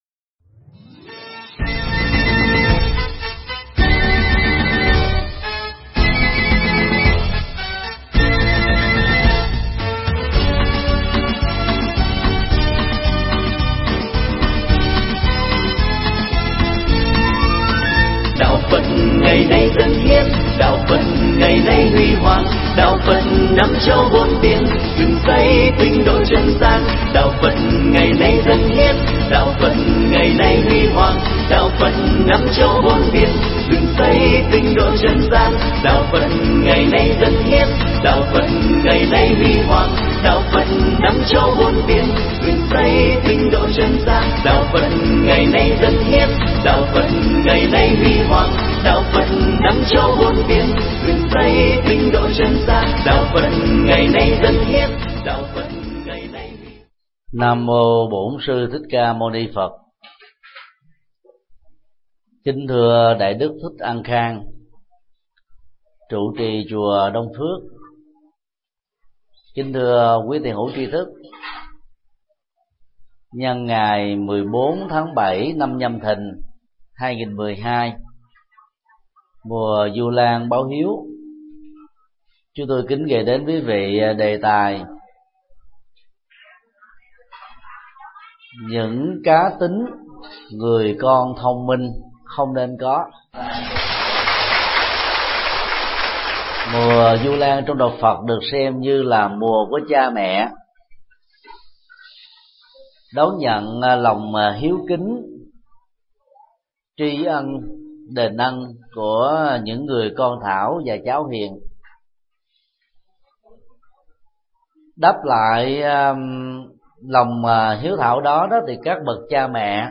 Mp3 Pháp Thoại Những cá tính người con thông minh không nên có
giảng tại chùa Đông Phước